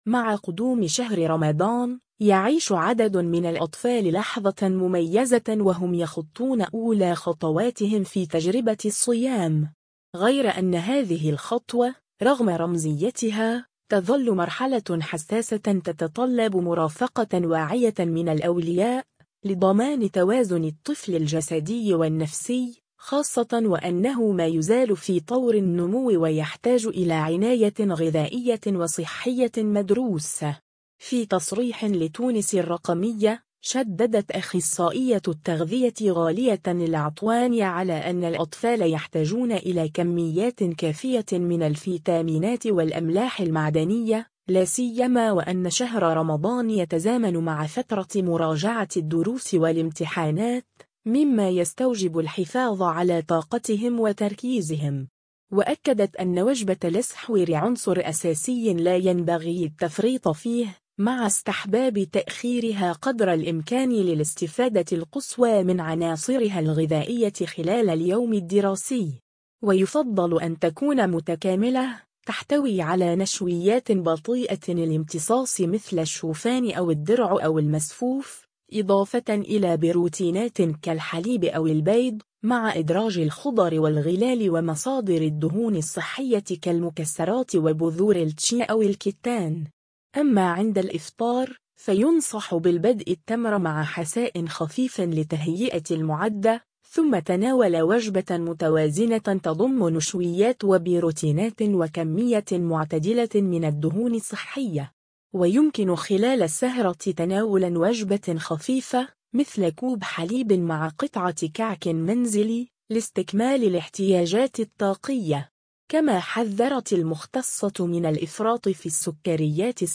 أخصائية التغذية